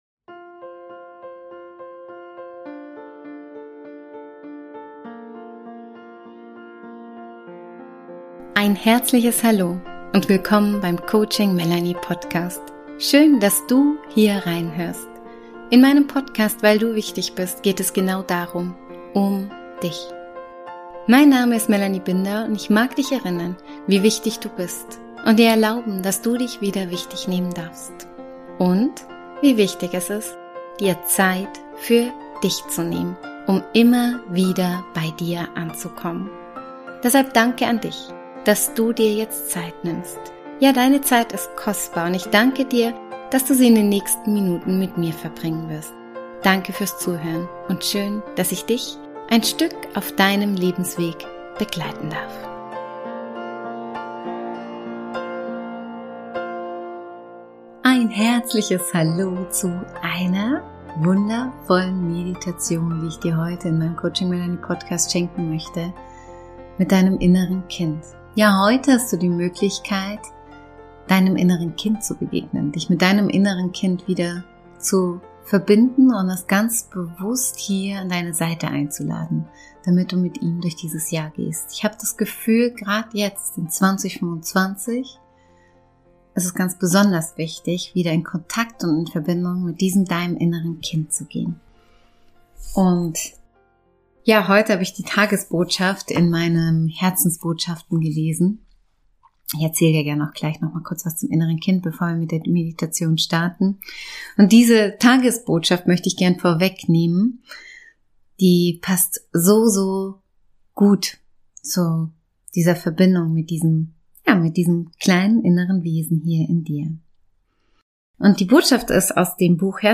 In dieser Folge lade ich dich ein, dein inneres Kind kennenzulernen und in einer geführten Meditation Kontakt mit ihm aufzunehmen.